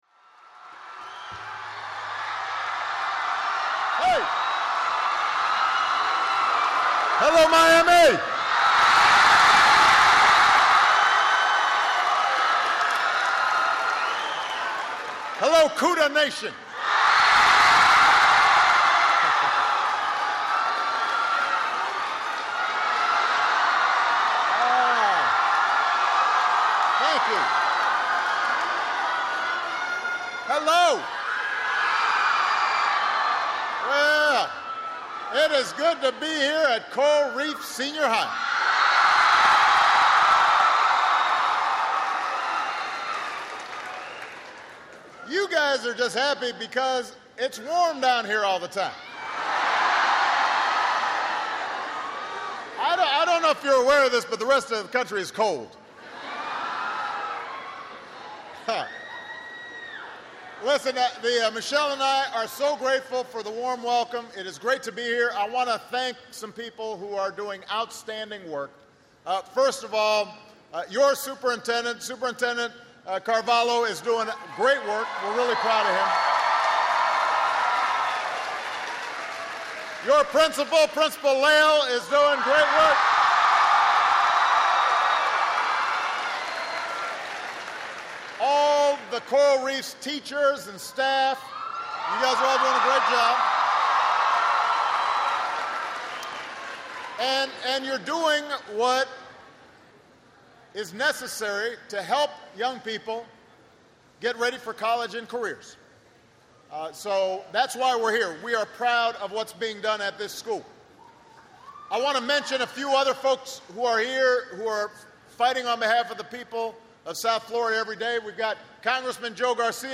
U.S. President Barack Obama talks about college accessibility and affordability at Coral Reef Senior High School in Miami, Florida